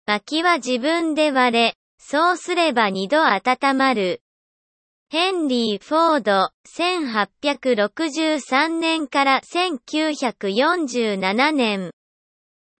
(Google Translateのエンジンを使用した、Sound of Textによるテキスト読み上げ)